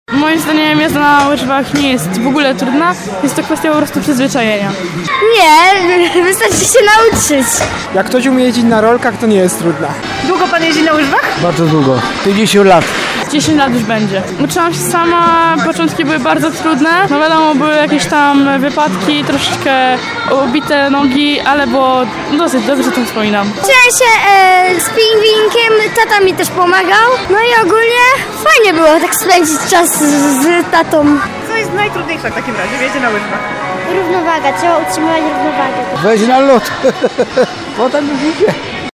Miłośnicy tego zimowego sportu, z którymi rozmawiała nasza reporterka przyznają, że początki bywają trudne, ale warto się przełamać.